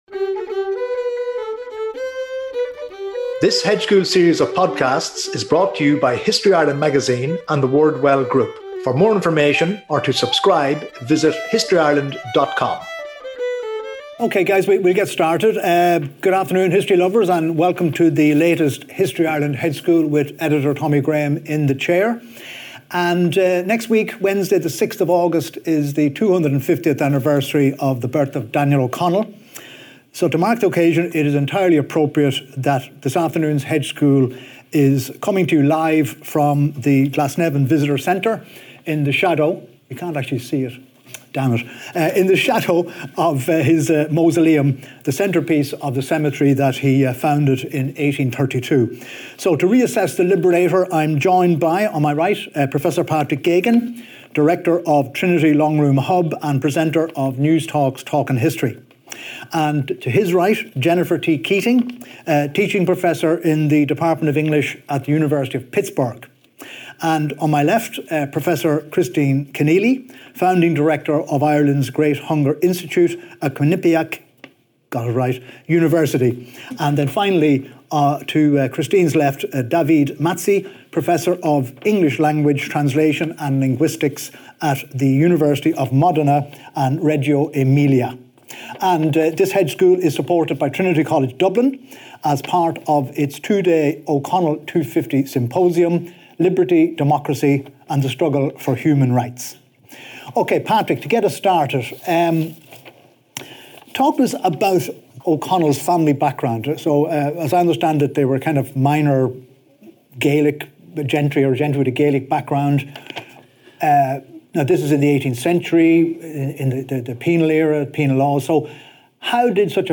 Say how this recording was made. (Recorded live on Wednesday 30 July ’25 @ Glasnevin Cemetery visitor centre)